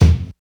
Focused Kick Drum E Key 210.wav
Royality free bass drum single shot tuned to the E note. Loudest frequency: 292Hz
focused-kick-drum-e-key-210-ha9.mp3